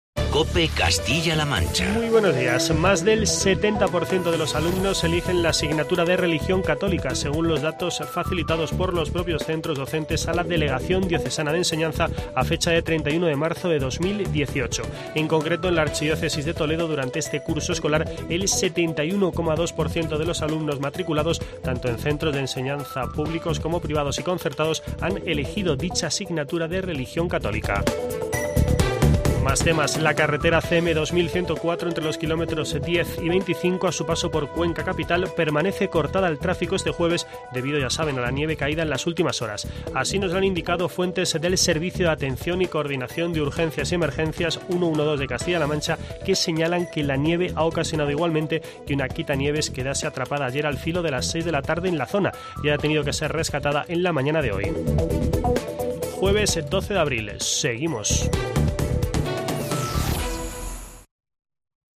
Boletín informativo de COPE Castilla-La Mancha.